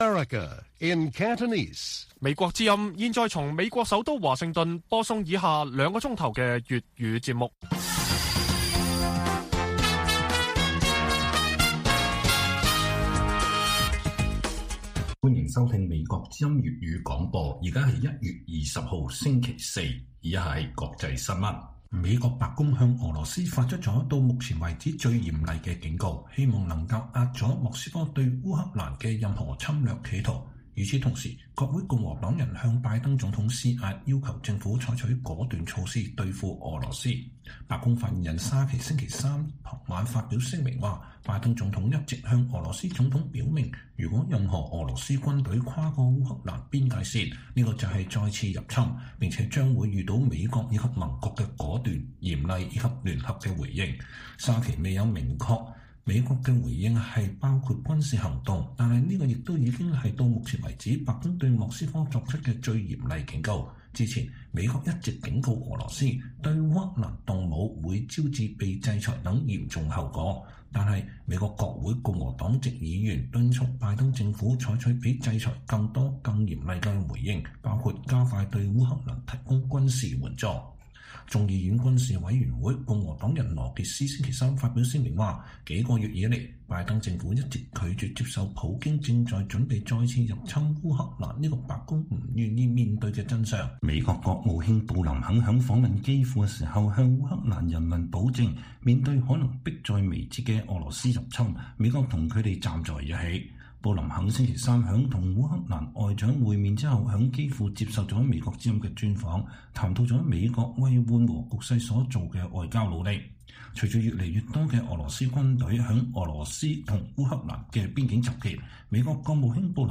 粵語新聞 晚上9-10點 : 專訪布林肯：俄羅斯何去何從有明確選擇